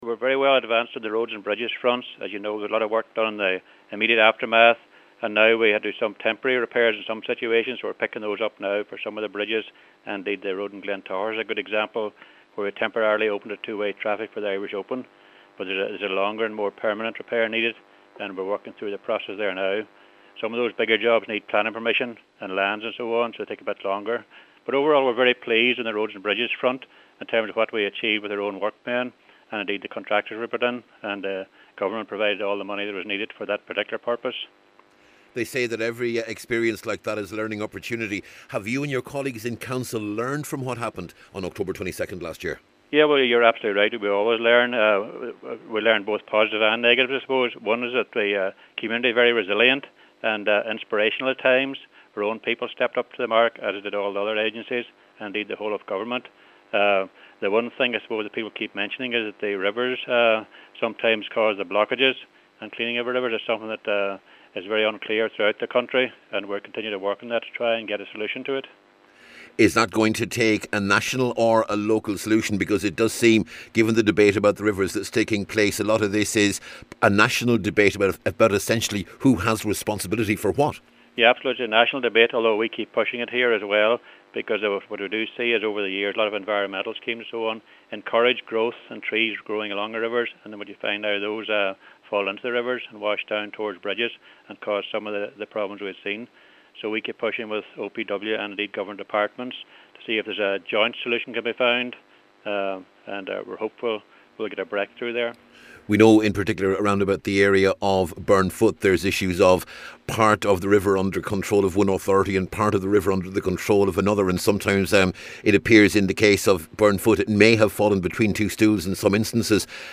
He’s been speaking to Highland Radio News……..